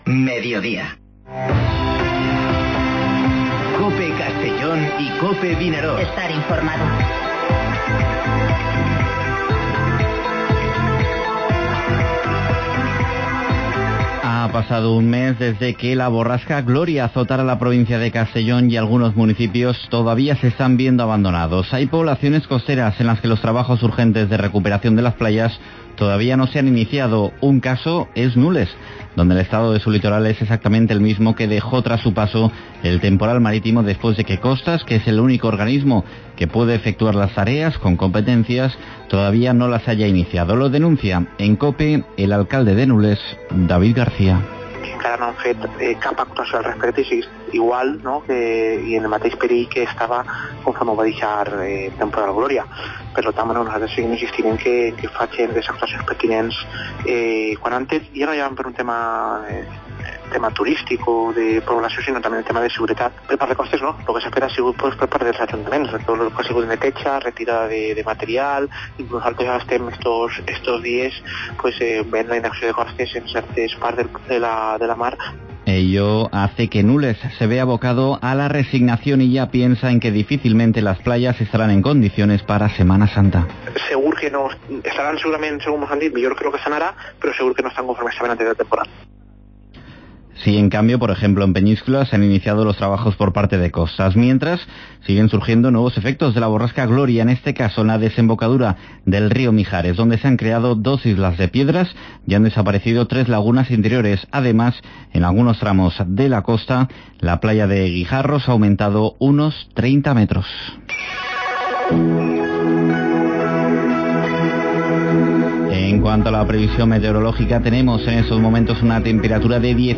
Informativo Mediodía COPE en la provincia de Castellón (20/02/20)